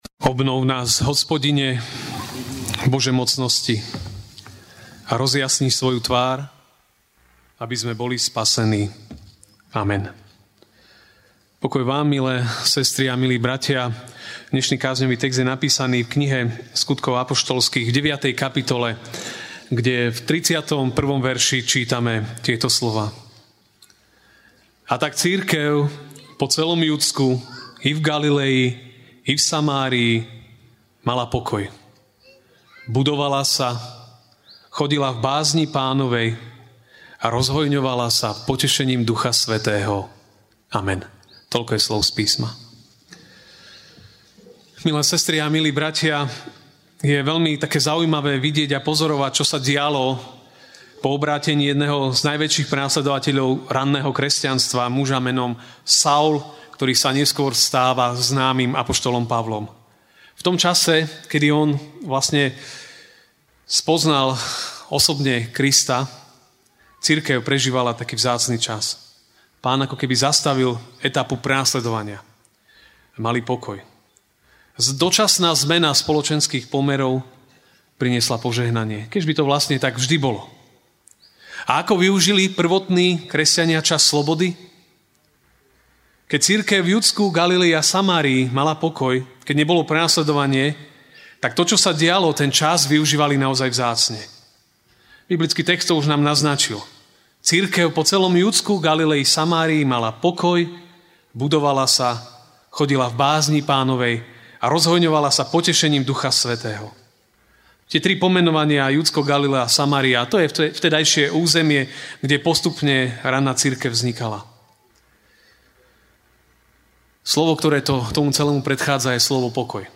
Špeciálna kázeň: Seniorátny deň - Slobodne a radostne (Sk. 9, 31)10